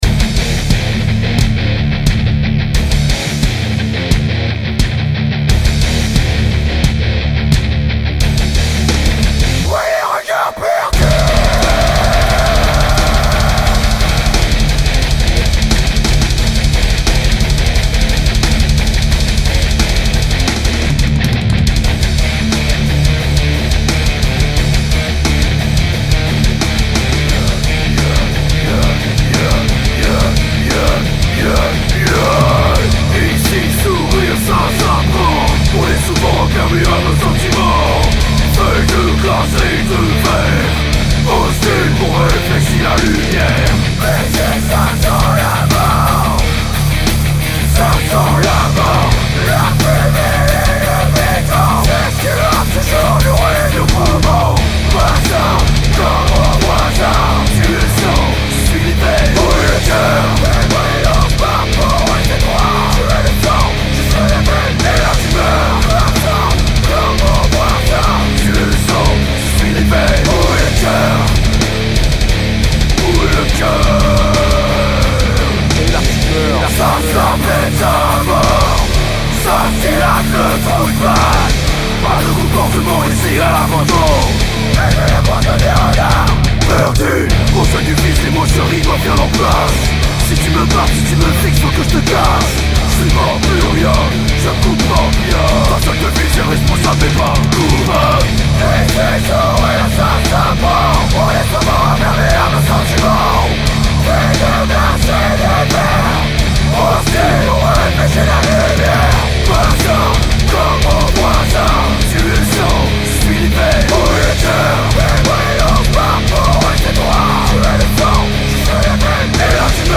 Les riffs sont lourds.